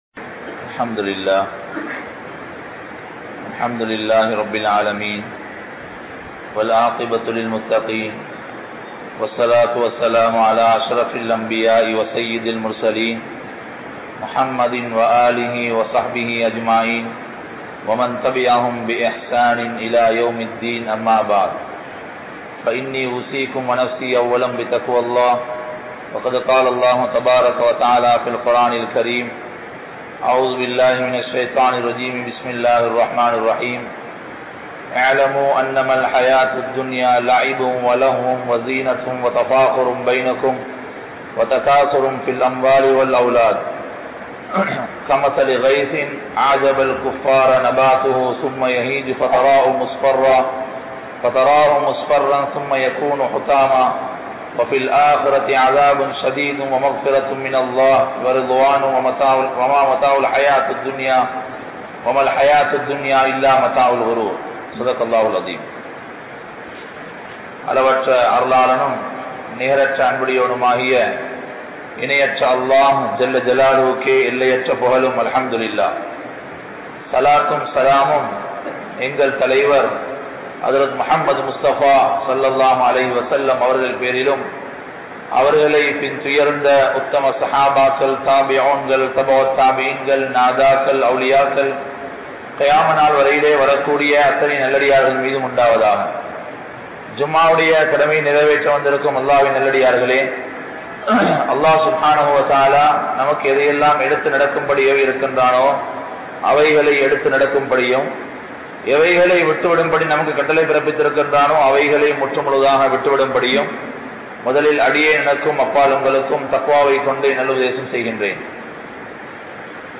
Haraamaana Valimuraihal | Audio Bayans | All Ceylon Muslim Youth Community | Addalaichenai
Junction Jumua Masjith